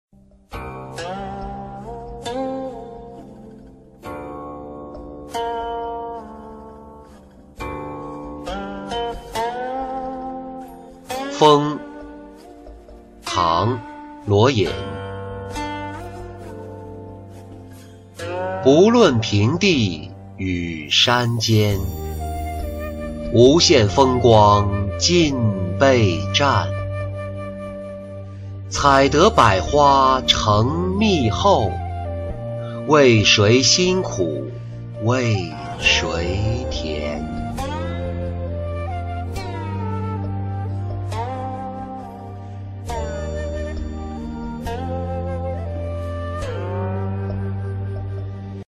蜂-音频朗读